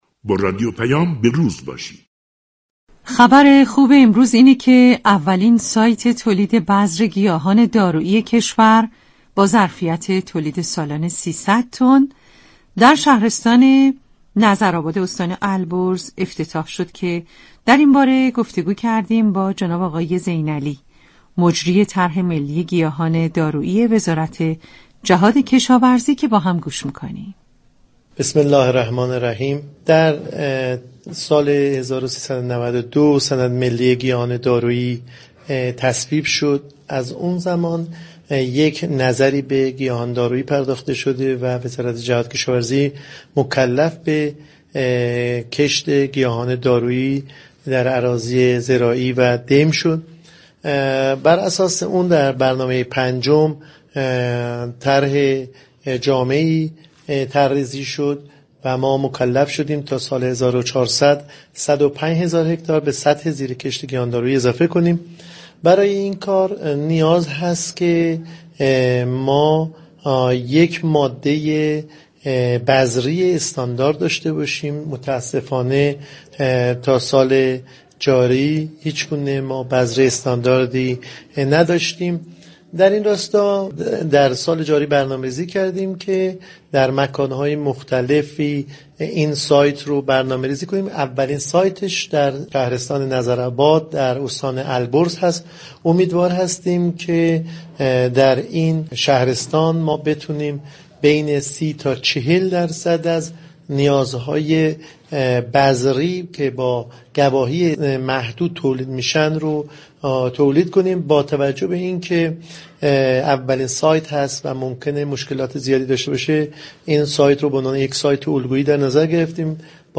در گفتگو با رادیو پیام